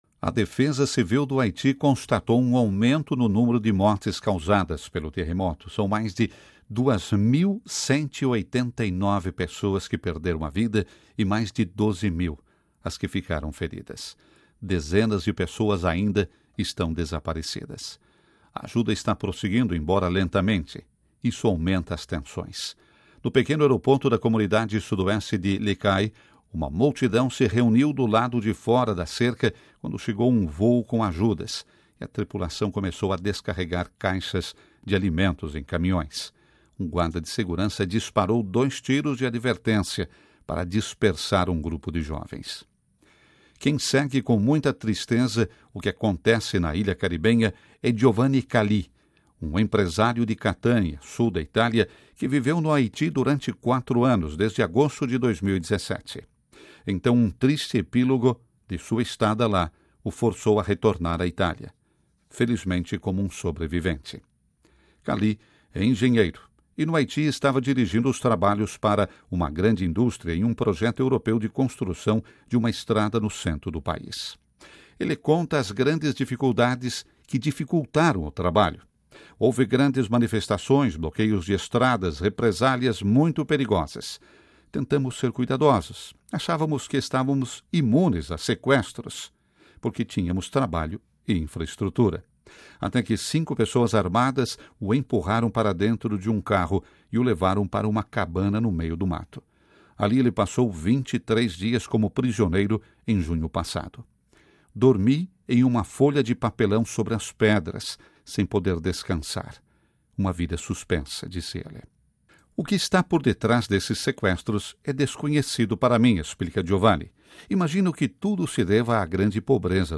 Aqui a voz rompe com a emoção.